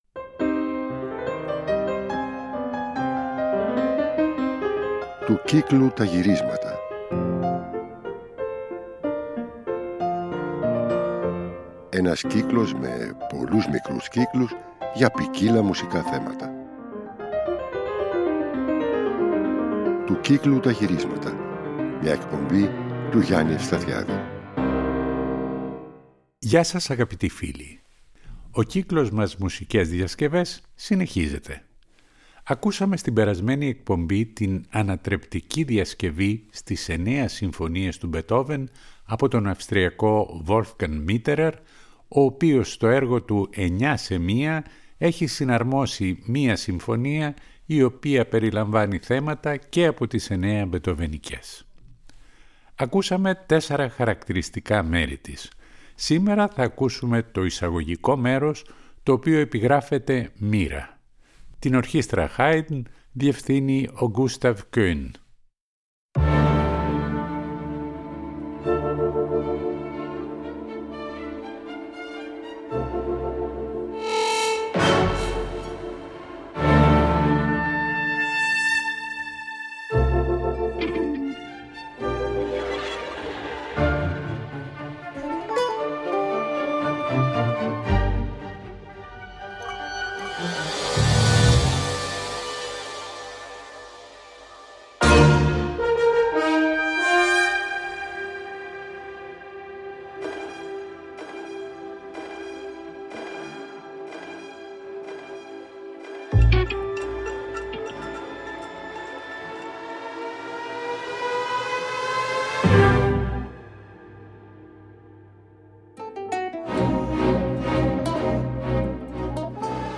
ορχηστρικές διασκευές